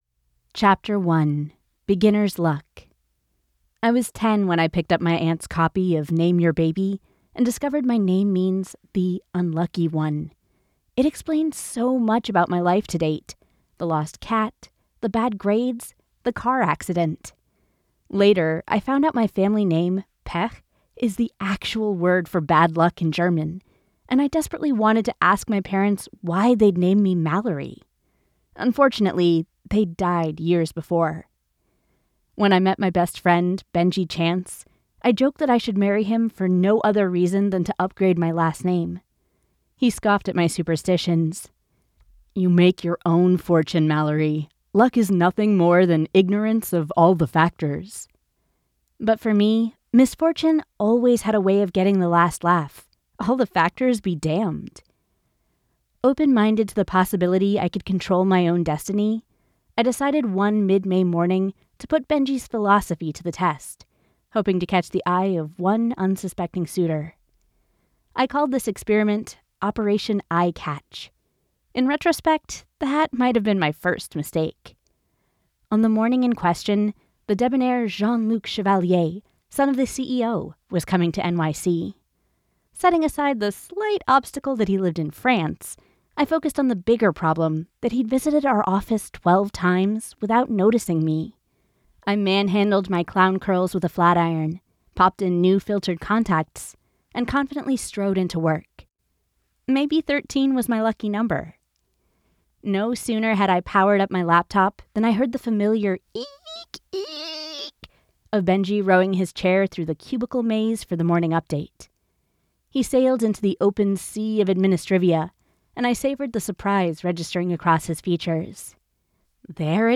Falling In Luck audiobook sample